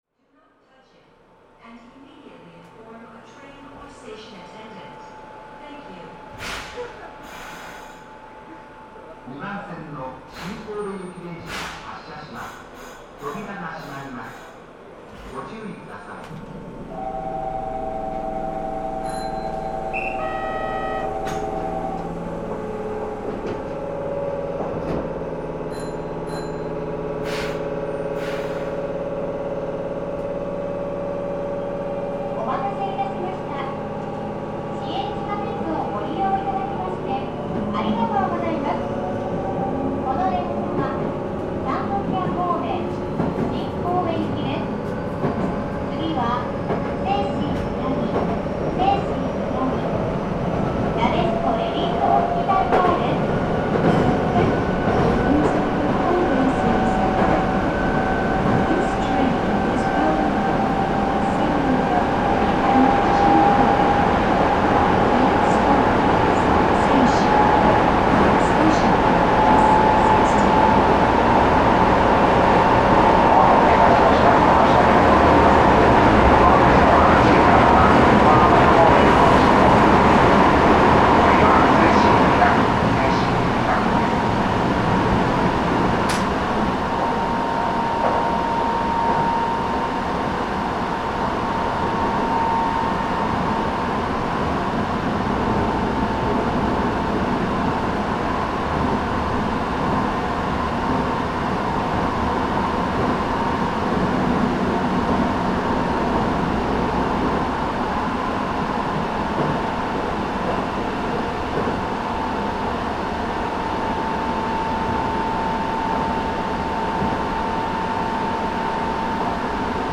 神戸市交通局 1000形 ・ 走行音(GTO) (1.61MB) 収録区間：総合運動公園→伊川谷 制御方式：VVVFインバータ制御(日立GTO後期) ・ 走行音(IGBT・全区間) (56.3MB*) 収録区間：西神中央→谷上 制御方式：VVVFインバータ制御(日立2レベルIGBT) 1977(昭和52)年、西神・山手線開業とともに登場した車両。